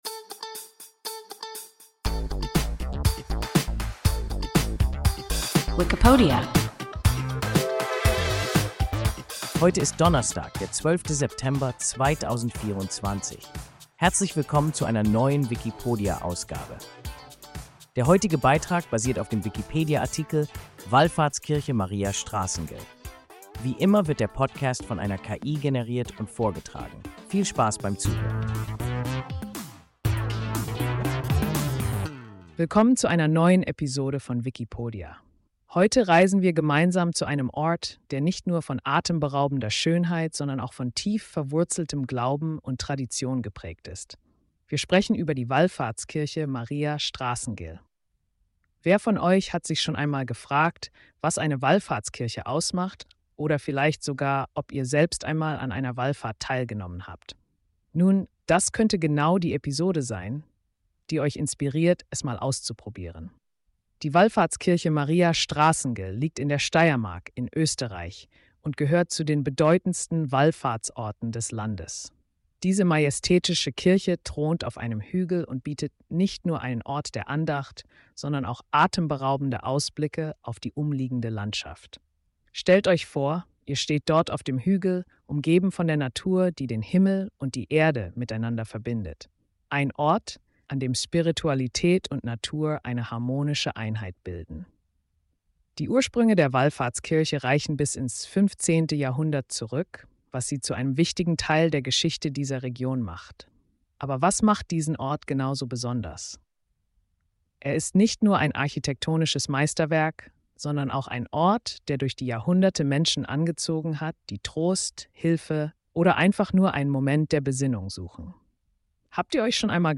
Wallfahrtskirche Maria Straßengel – WIKIPODIA – ein KI Podcast